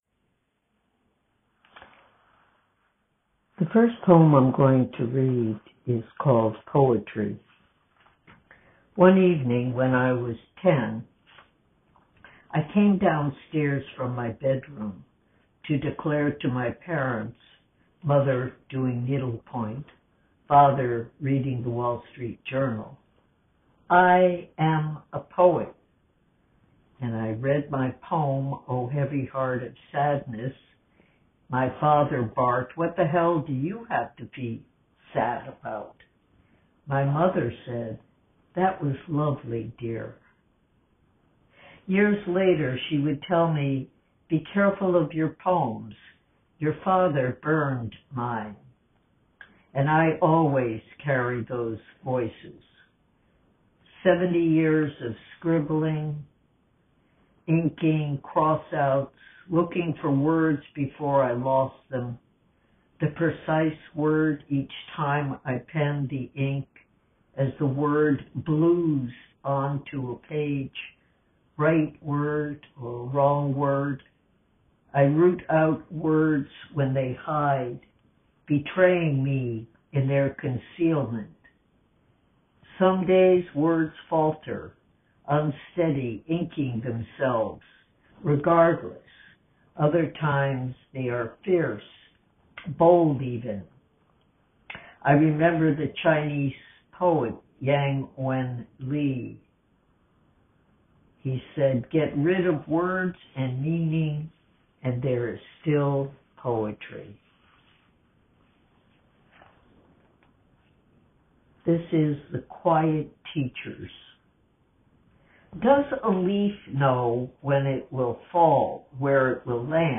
Poetry Reading
UConn Barnes & Noble Bookstore (4/13/19) View Flier Here